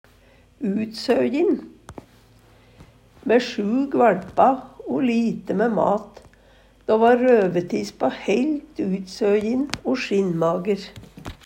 utsøjin - Numedalsmål (en-US)